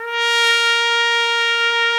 Index of /90_sSampleCDs/Roland L-CD702/VOL-2/BRS_Piccolo Tpt/BRS_Picc.Tp 2 M